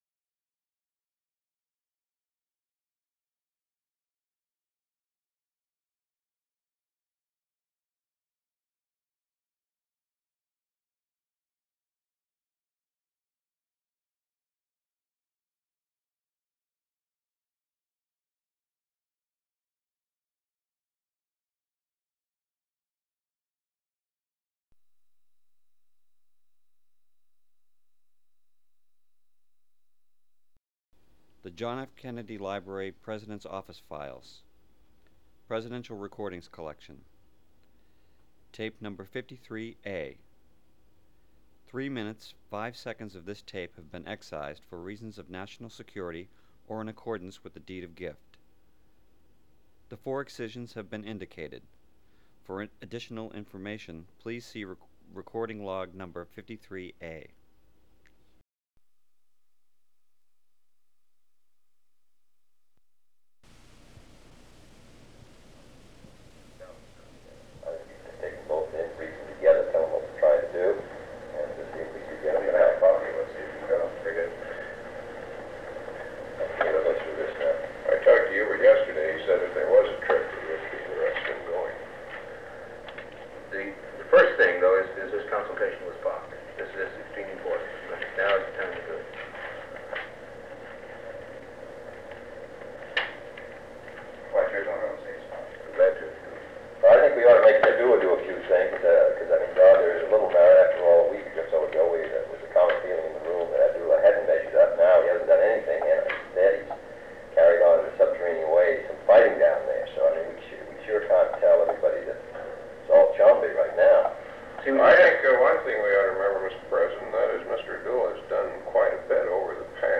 Secret White House Tapes | John F. Kennedy Presidency Meeting on the Congo (cont.)